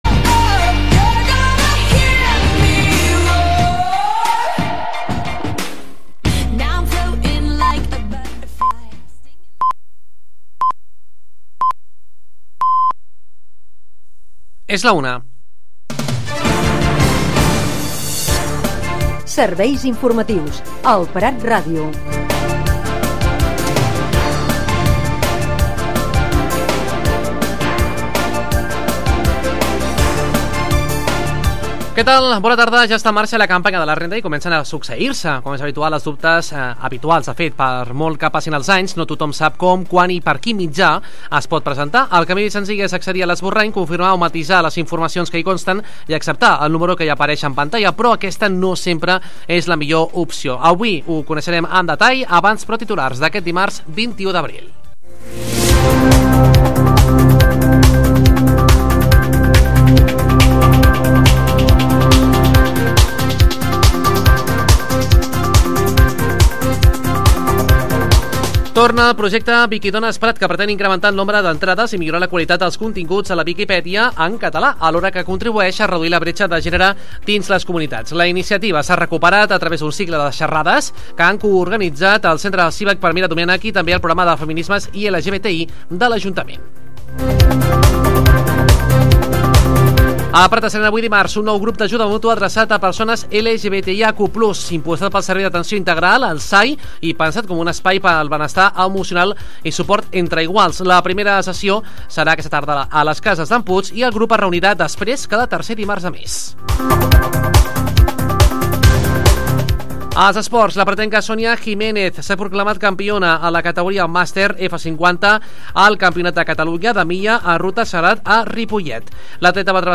Espai informatiu d'elprat.ràdio, amb tota l'actualitat local i de proximitat.